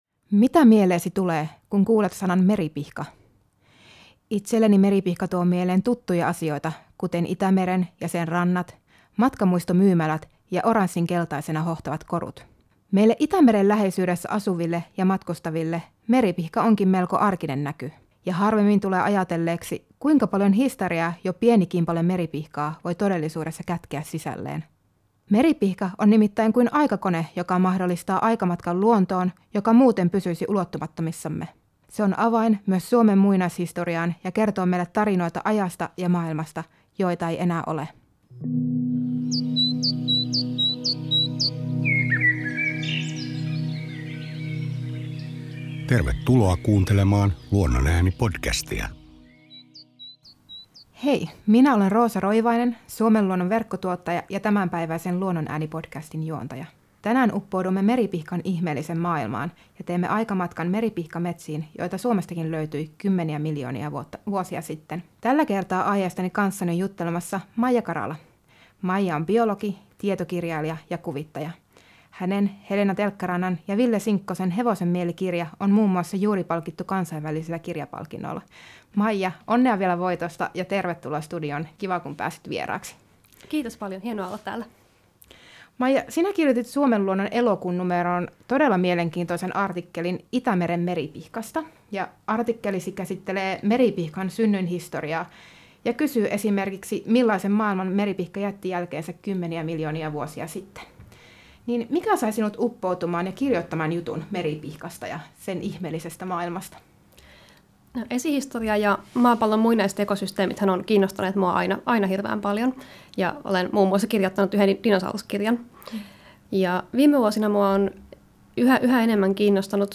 juttelemassa biologi, tietokirjailja ja kuvittaja